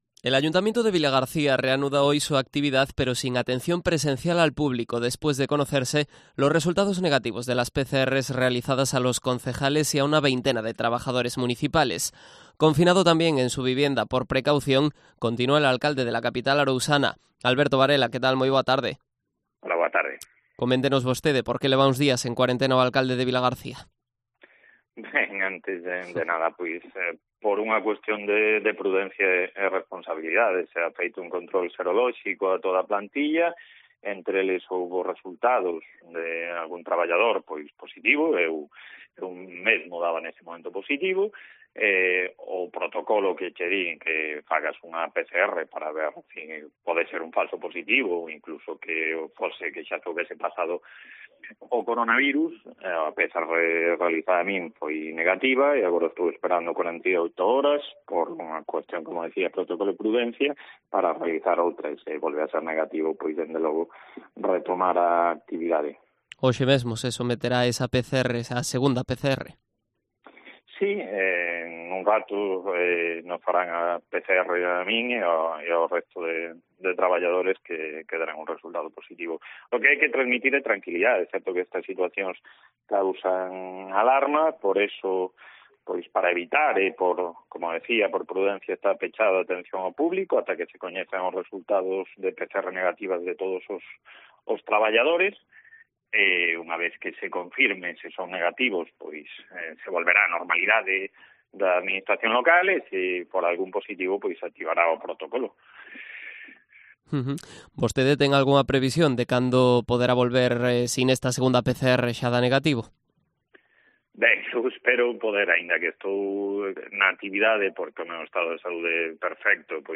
Entrevista a Alberto Varela, alcalde de Vilagarcía